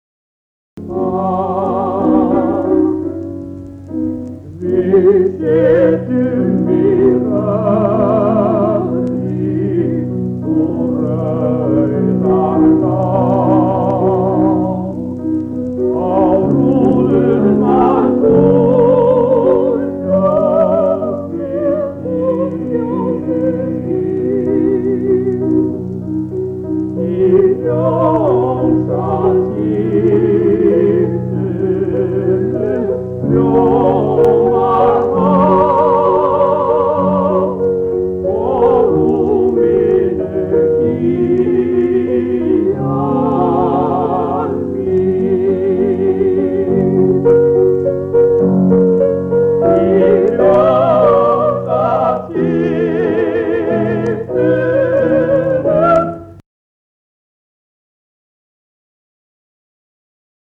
Dúett